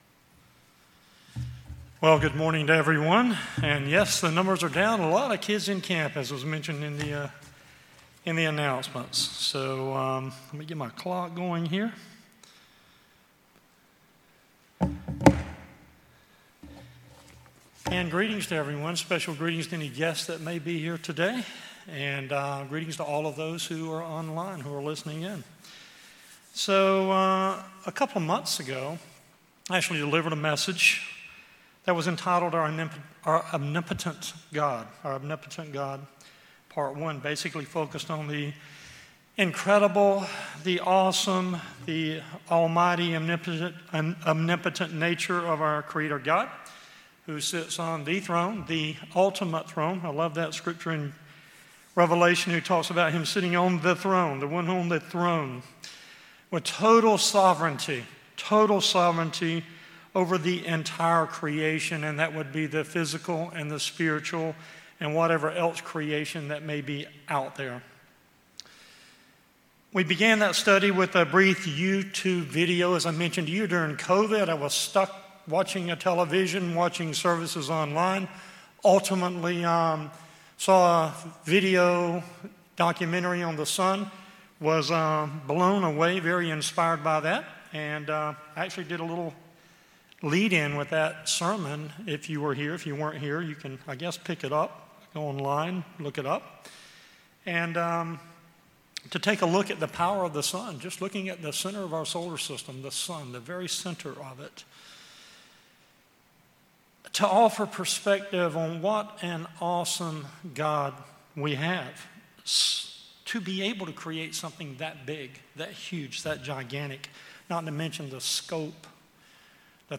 This Part 2 message considers how GOD’s Omnipotence can be revealed and understood by others through HIS children! The takeaway for devoted believers, followers, and worshippers of our Supreme Limitless Omnipotent GOD is the potential that we too can be agents of the revelation of GOD’s Incredible Incomparable Omnipotence!